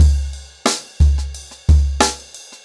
InDaHouse-90BPM.7.wav